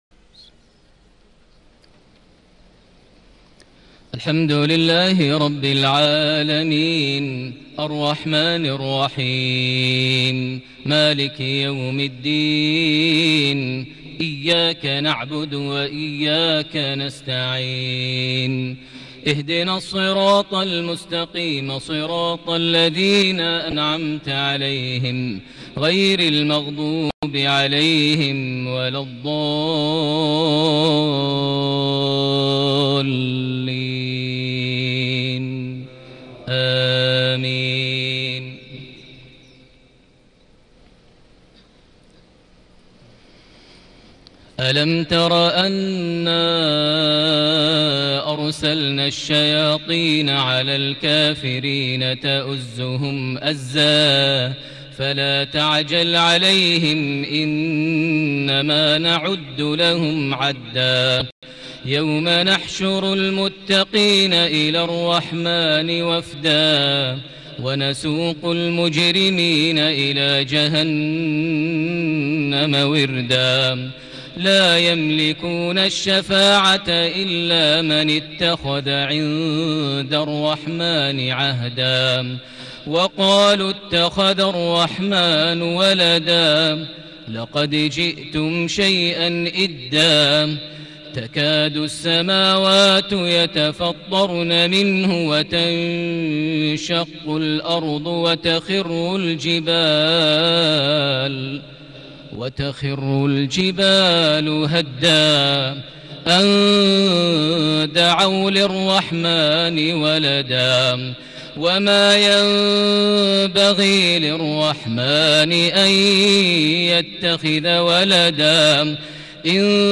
صلاة مغرب ٢٣ محرم ١٤٤٠هـ خواتيم سورة مريم > 1440 هـ > الفروض - تلاوات ماهر المعيقلي